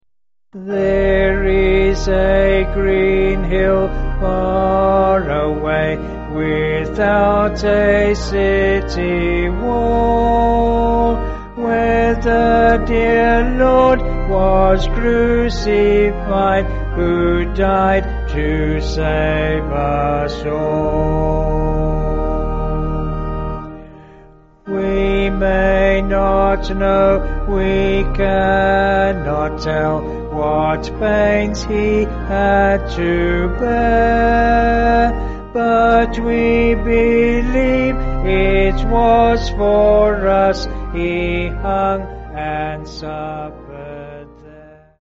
(BH)   5/Eb
Vocals and Organ